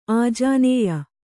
♪ ājānēya